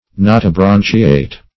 Search Result for " notobranchiate" : The Collaborative International Dictionary of English v.0.48: Notobranchiate \No`to*bran"chi*ate\, a. (Zool.) Of or pertaining to the Notobranchiata.